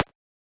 generic-hover.wav